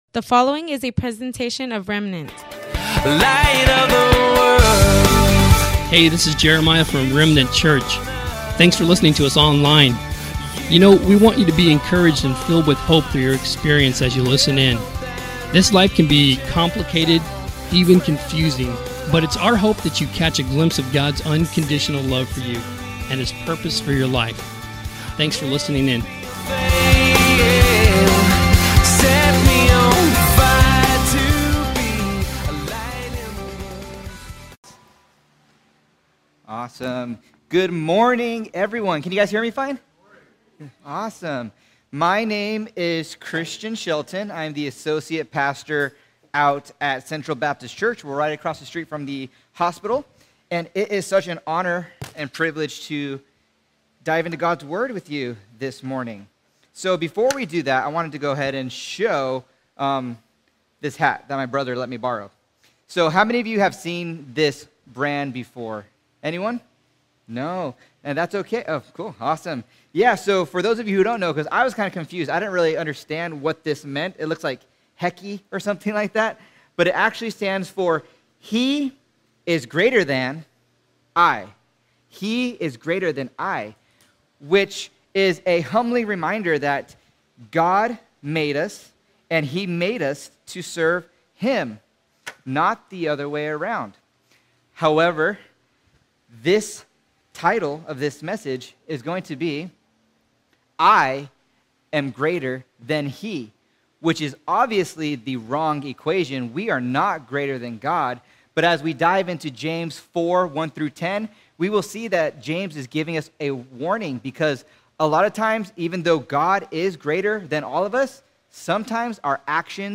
Welcome to the livestream of our worship gathering at Remnant Church in Imperial Valley, CA. Today